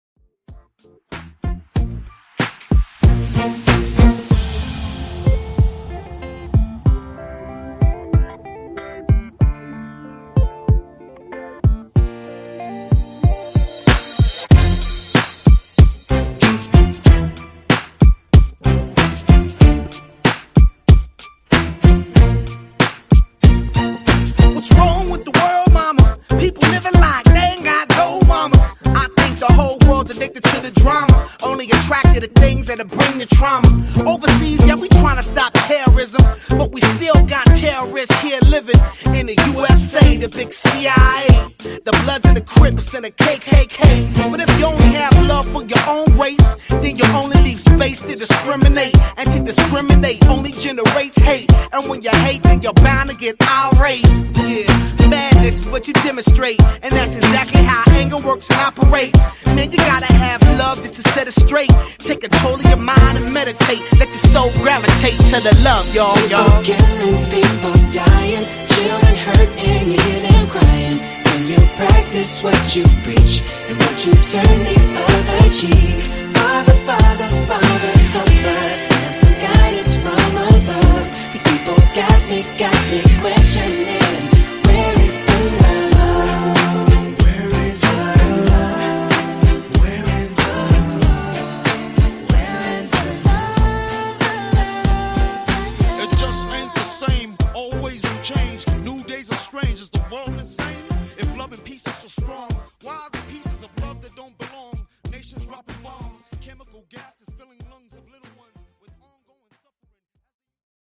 Genre: RE-DRUM Version: Clean BPM: 125 Time